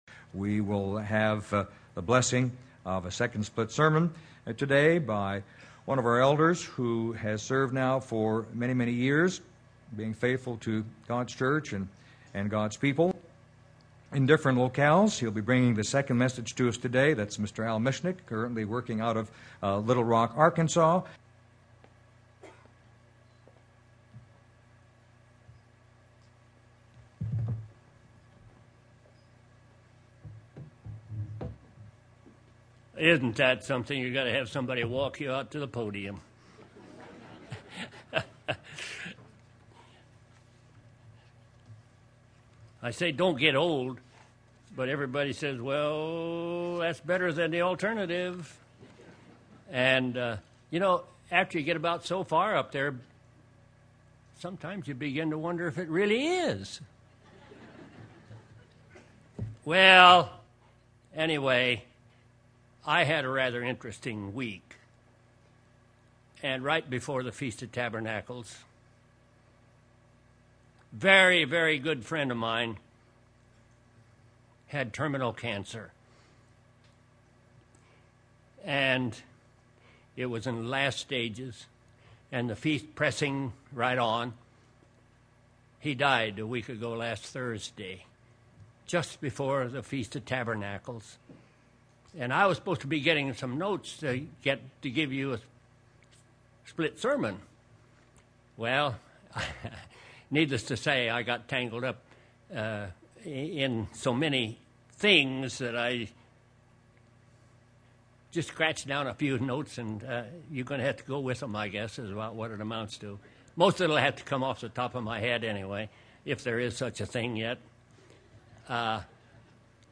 This sermon was given at the Branson, Missouri 2012 Feast site.